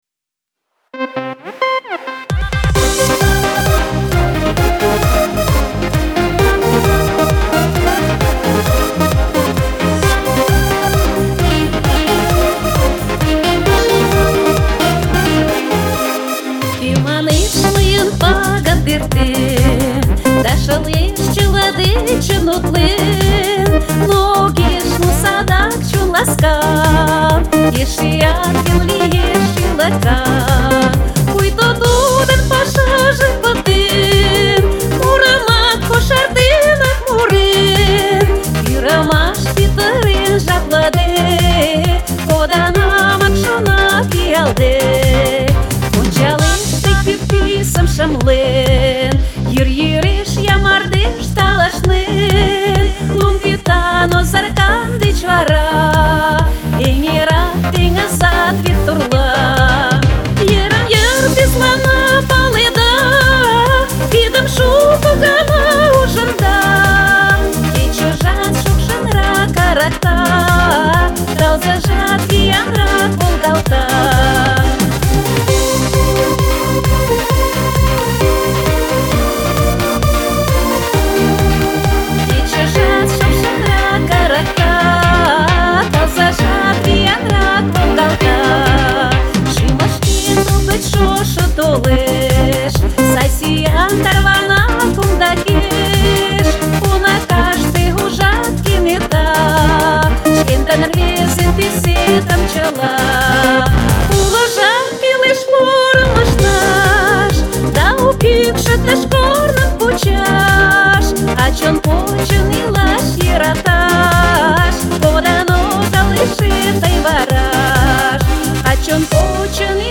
аранжировка и звукозапись- моя